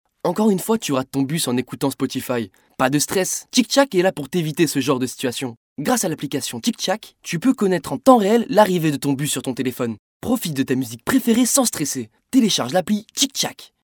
Voix jeune (sur demande)